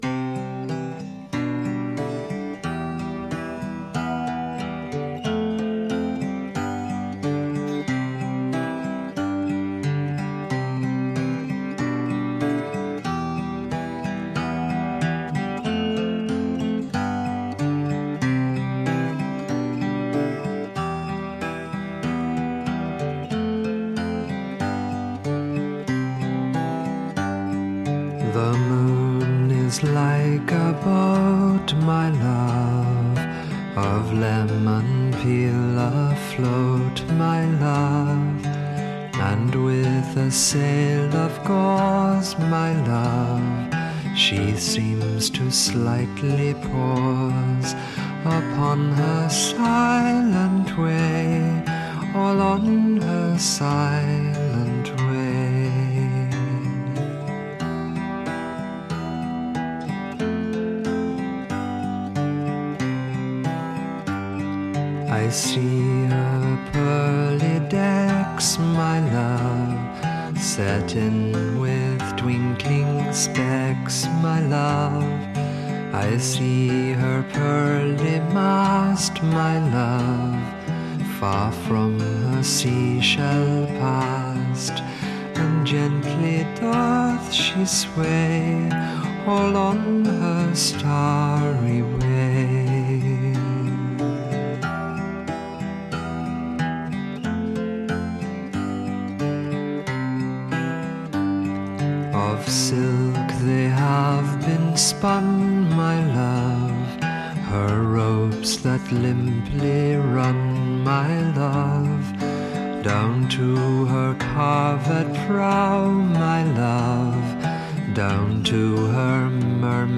It’s time for psychedelic folk hour.
gentle ballad